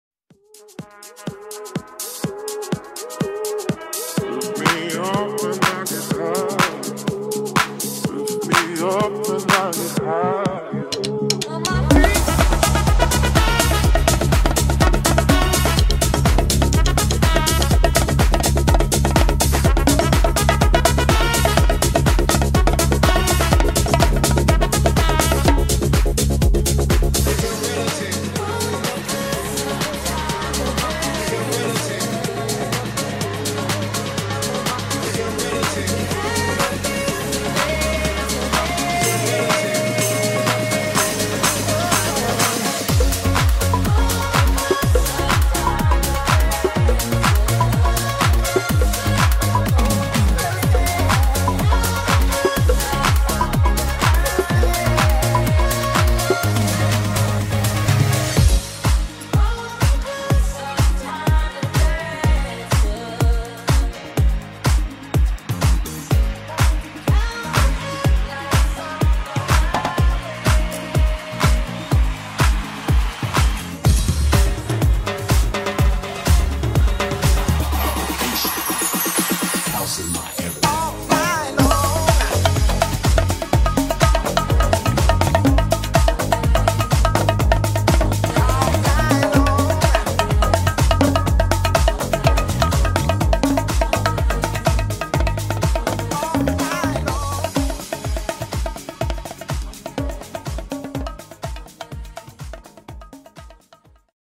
• Standout combination of DJ & live instruments
• Dancefloor-focused sets to keep the party moving